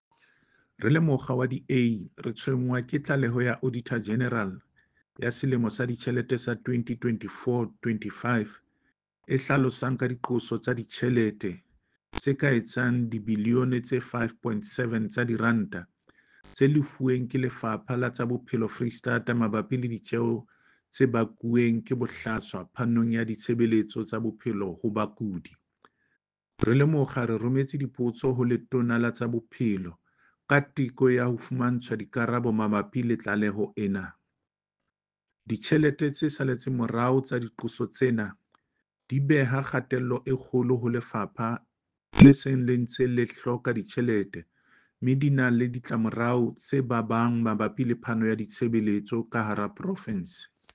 Sesotho soundbites by David Masoeu MPL and